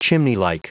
Prononciation du mot chimneylike en anglais (fichier audio)
Prononciation du mot : chimneylike